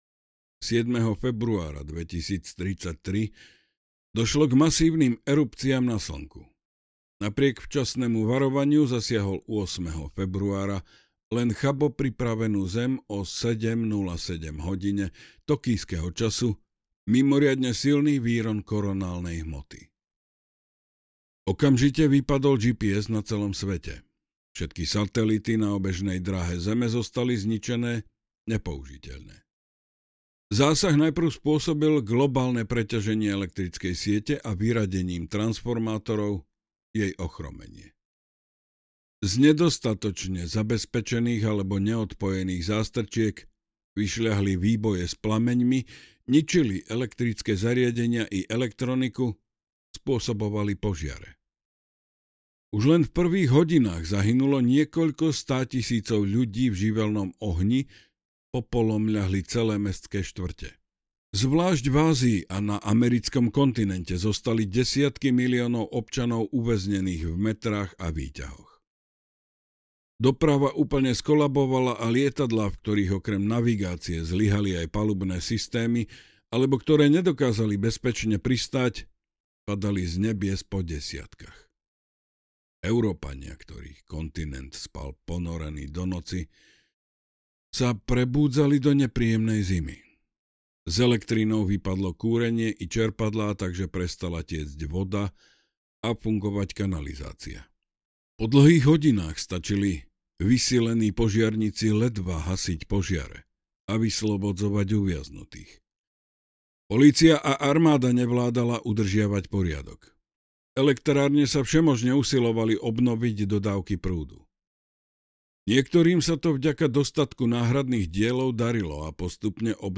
2084 audiokniha
Ukázka z knihy